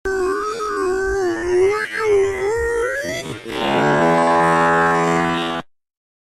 brain fart slowed